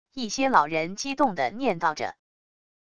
一些老人激动的念叨着wav音频